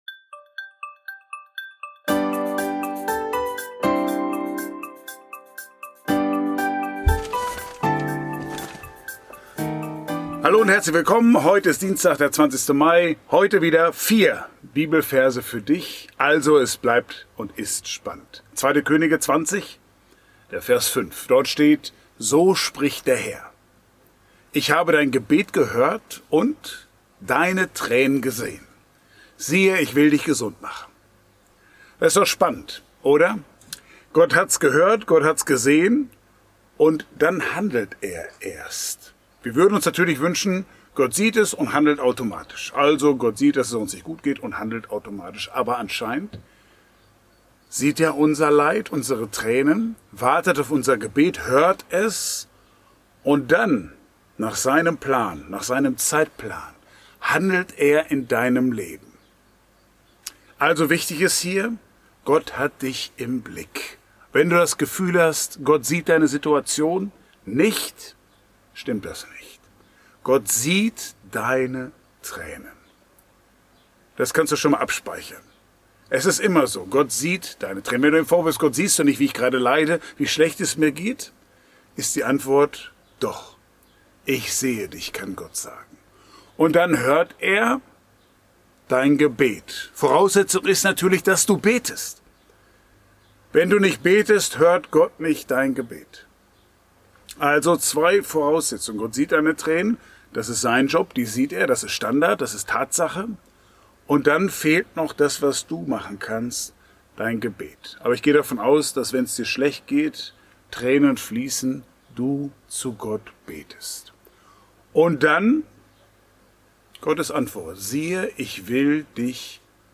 Ein täglicher Impuls